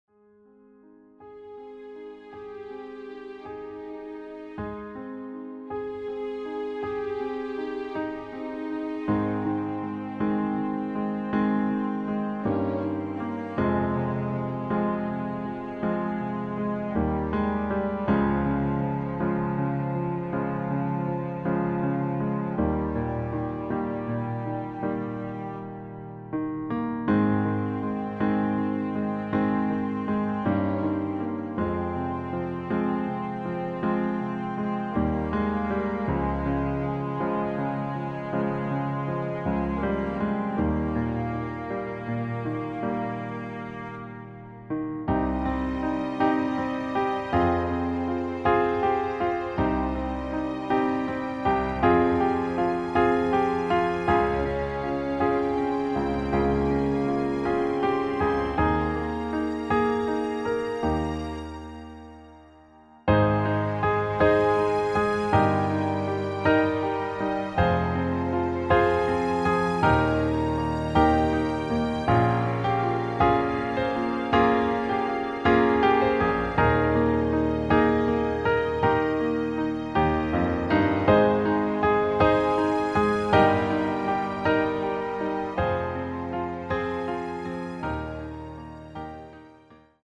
Klavierversion- Instrumental
• Art: Klavier Streicher Version
• Das Instrumental beinhaltet NICHT die Leadstimme
Klavier / Streicher